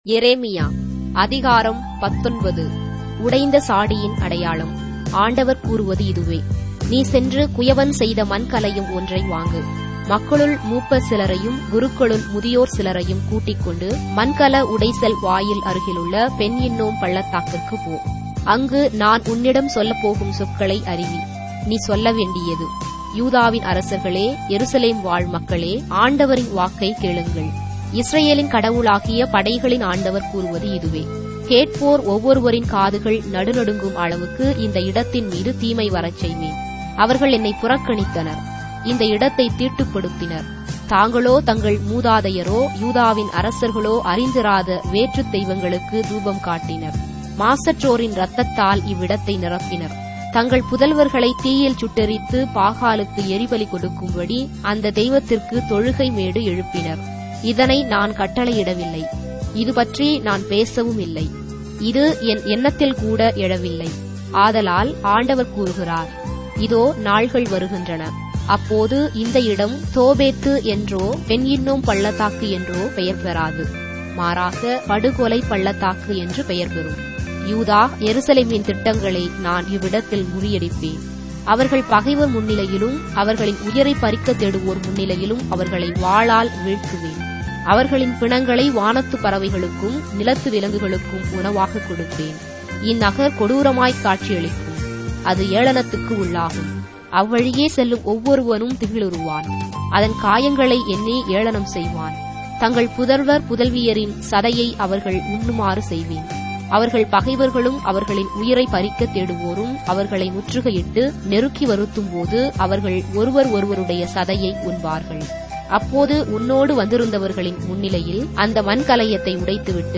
Tamil Audio Bible - Jeremiah 7 in Ecta bible version